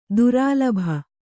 ♪ durālabha